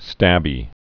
(stăbē)